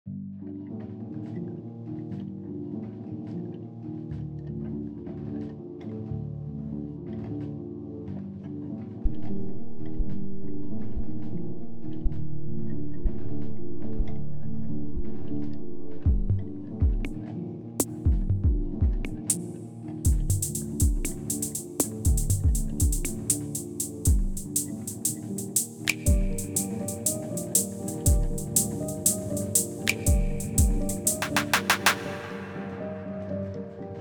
Synthesiser draws me in to this piece, and I it evokes lots of emotion.
I’m on edge.